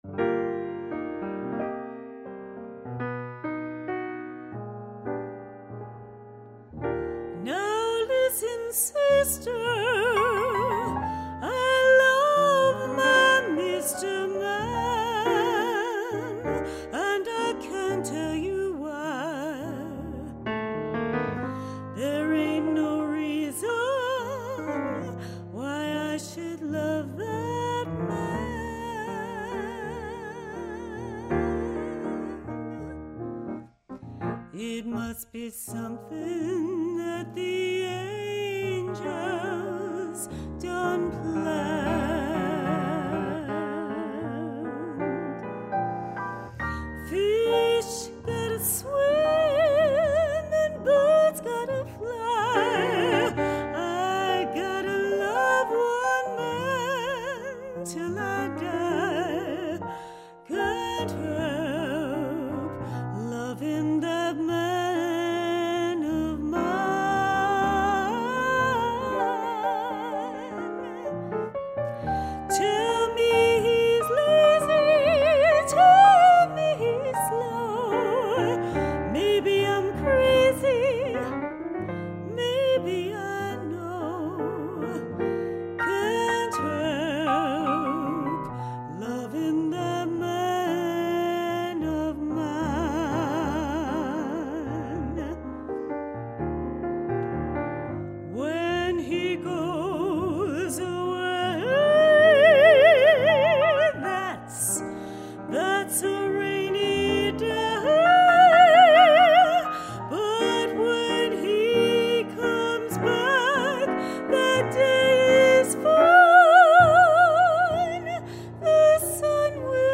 Est Incarnatus Est (Mozart) - May 2006 Concert
Flute
Piano